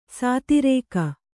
♪ sātirēka